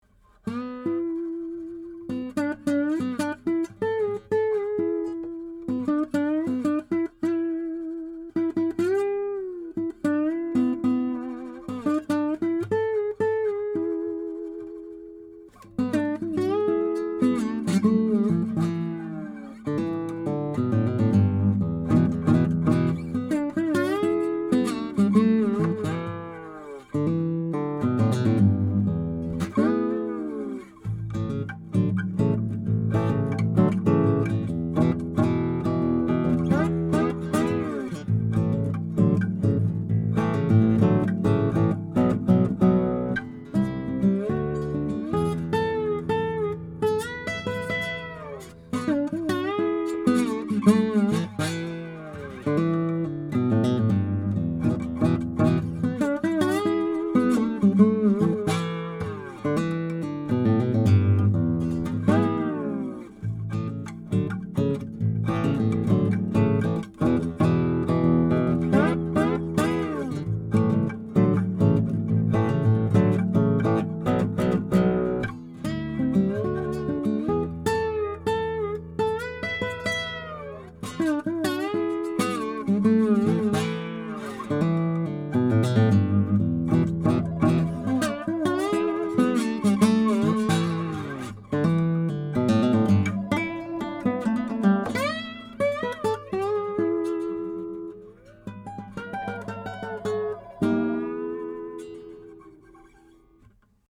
NEW 10-String Cathedral Guitar MODEL 40
Here are 17 quick, 1-take MP3 sound files of me playing this guitar, to give you an idea of what to expect. The guitar has amazing sympathetic resonance and sustain, as well as excellent power and projection, beautiful bass responce, and a very even response across the registers. These MP3 files have no compression, EQ or reverb -- just straight signal, recorded through a pair of SAMAR/AKG C1000S condenser mic, into Ocean Audio mic preamps, into a Metric Halo ULN8 converter/interface.
(original composition)
C1002sOceanULN8Boogie.mp3